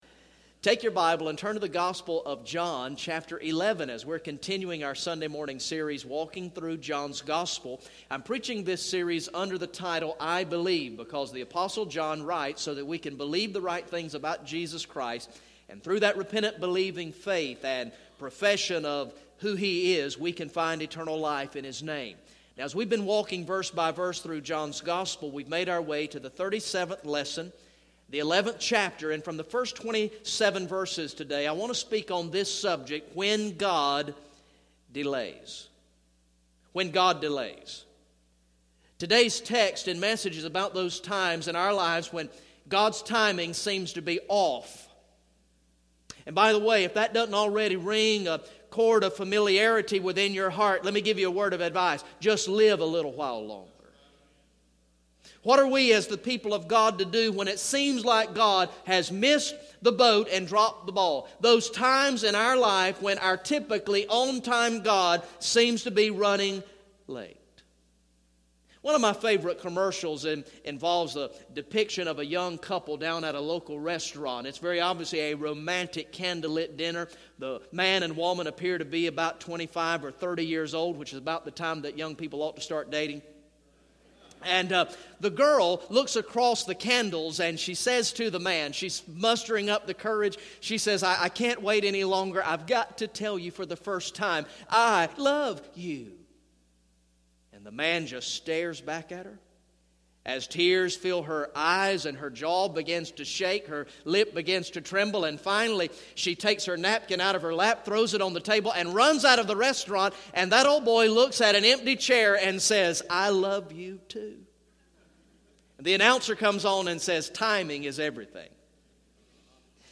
Message #37 from the sermon series through the gospel of John entitled "I Believe" Recorded in the morning worship service on Sunday, March 29, 2015